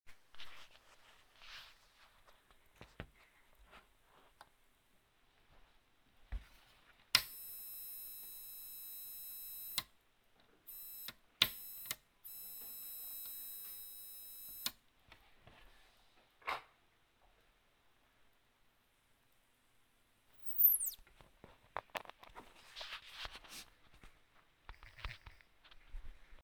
Habe die Platine erwärmt, jedoch immer noch das selbe, außer dass sich das Summer in der Tonlage geändert hat (tiefer).
Im Anhang hab ich ein Soundfile hochgeladen, bei dem man das SNT hört. Das Geräusch am Schluss gibt es, wenn ich den Stecker aus der Steckdose ziehe..